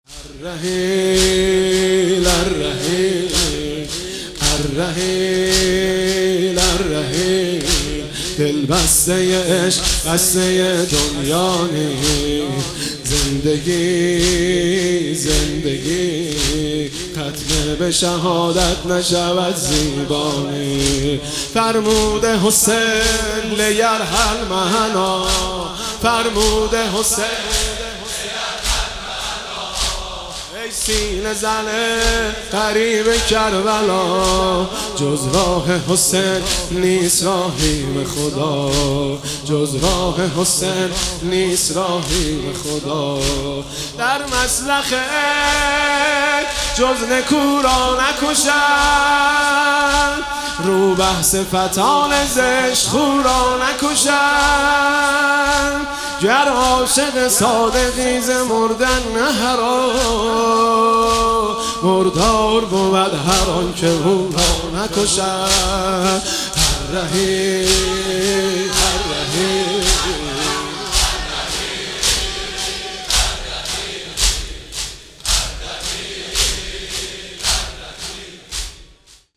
خیمه گاه - حاج مهدی رسولی - بخش دوم - پیش زمینه (الرحیل الرحیل دلبسته عشق)
شب دوم محرم 1397